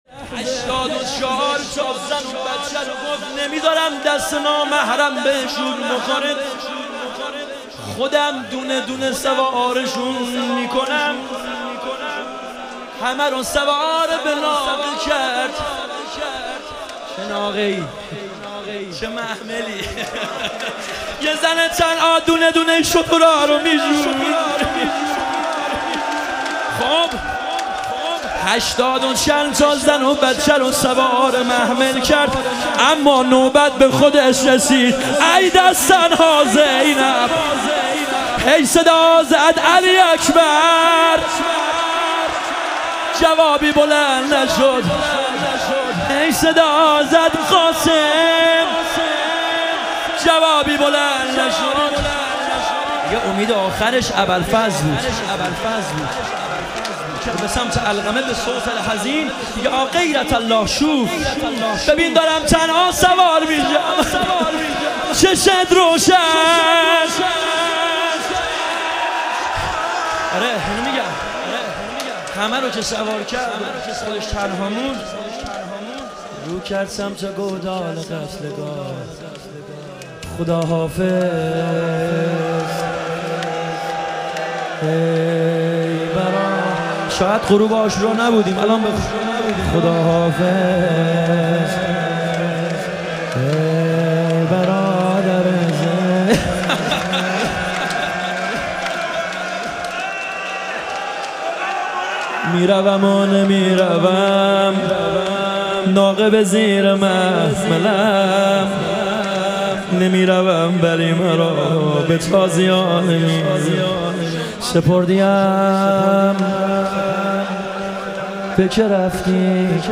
ایام فاطمیه اول - روضه